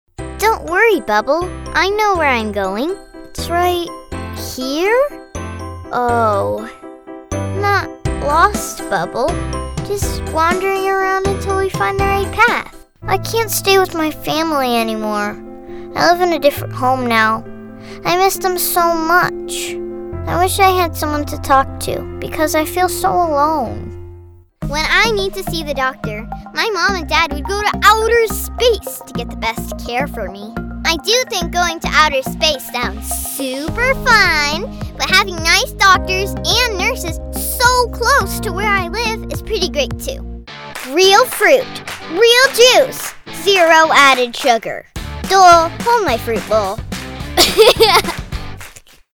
Kids & Teens